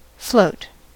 float: Wikimedia Commons US English Pronunciations
En-us-float.WAV